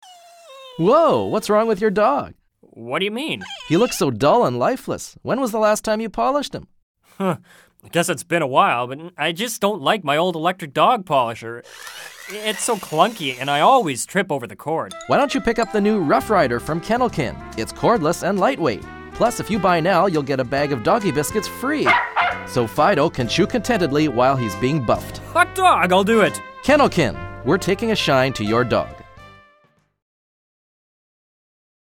VOICEOVER ON THAT WONDERFUL NEW DEVICE, THE ROUGH RIDER